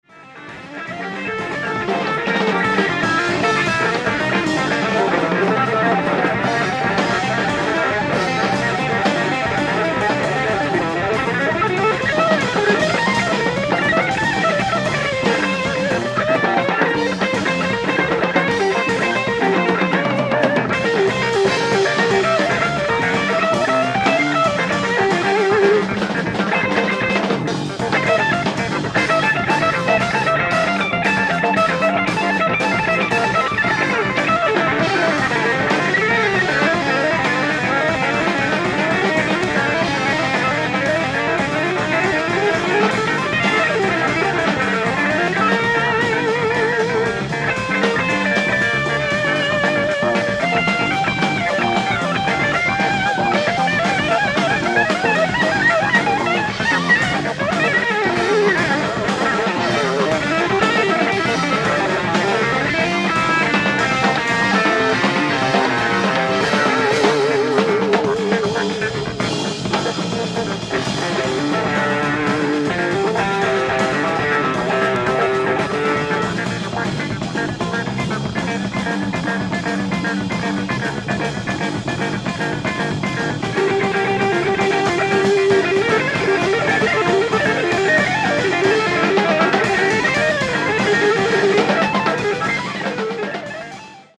ノイズレスのレストア済音源！！
※試聴用に実際より音質を落としています。